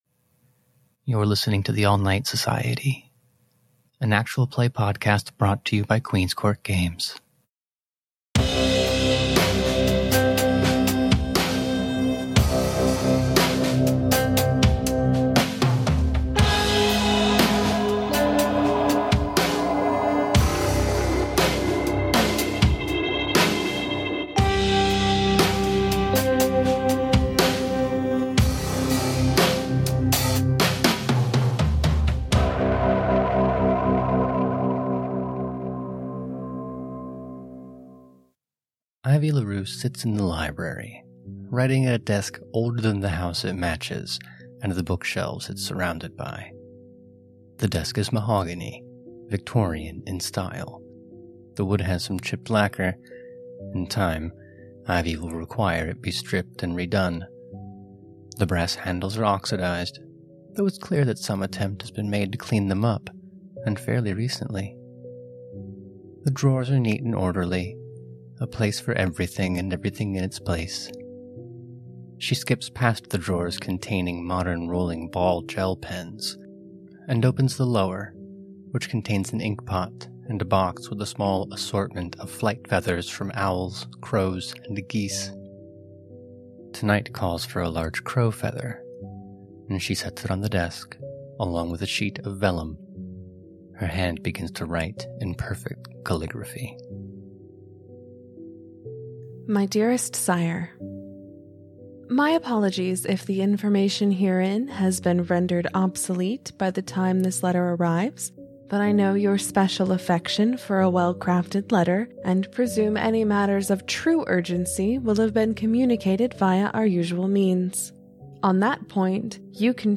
TANS-BONUS-Dear-Gabrielle-w-music.mp3